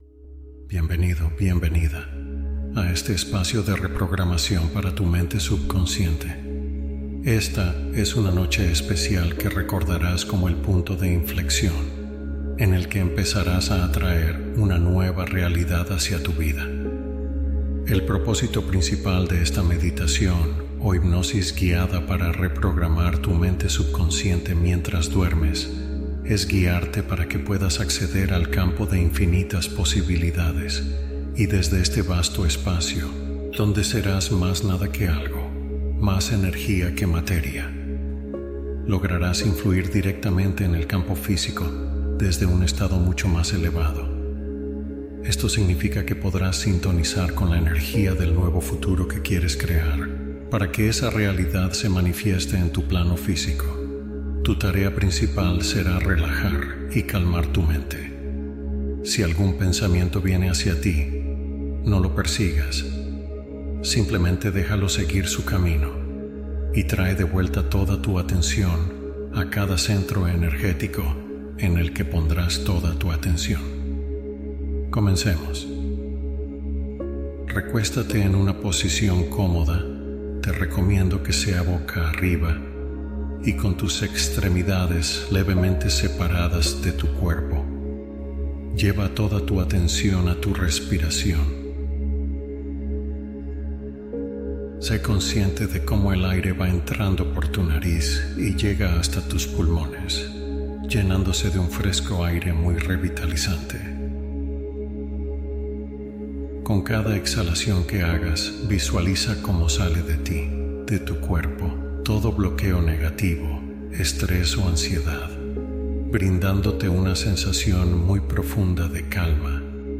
Crea una nueva realidad mientras duermes con esta hipnosis guiada